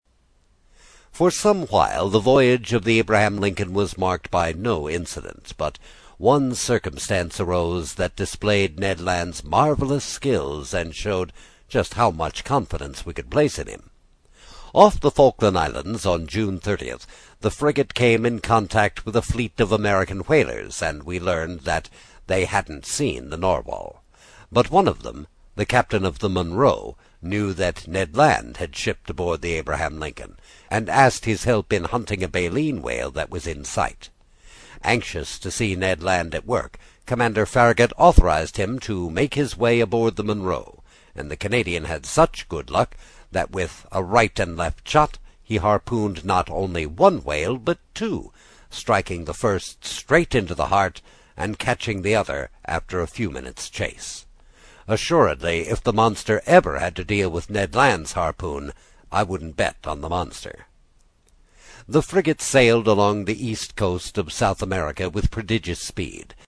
英语听书《海底两万里》第46期 第5章 冒险活动(1) 听力文件下载—在线英语听力室